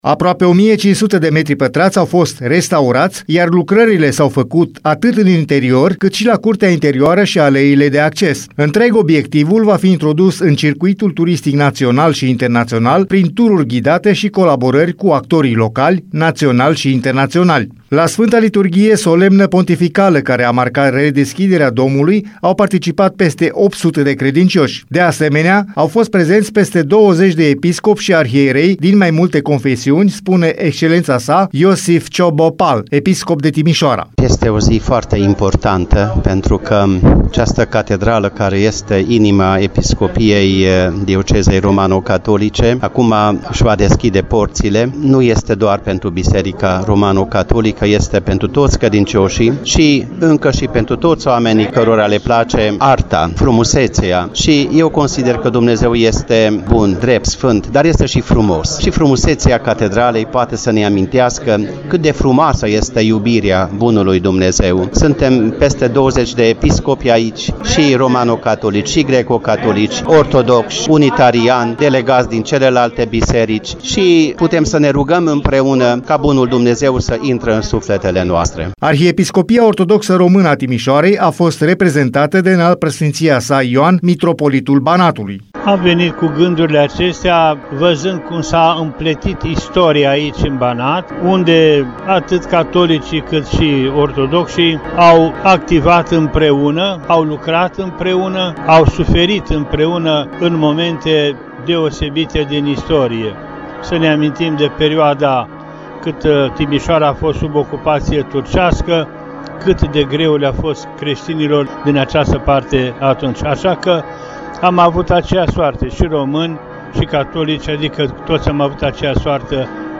Credincioșii au venit cu bucurie, dar și cu evlavie la acest eveniment, unde slujbele au fost oficiate în trei limbi, română, germană și maghiară.